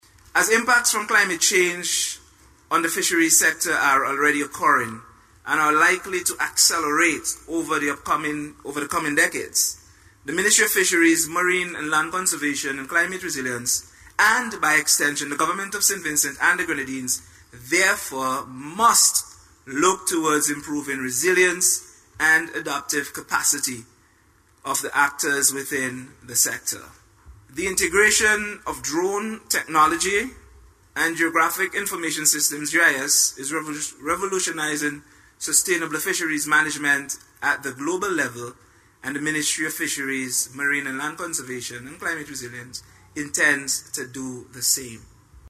That’s according to Portfolio Minister, Hon. Conroy Huggins as he contributed to the debate on the Budget Estimates in Parliament on Thursday.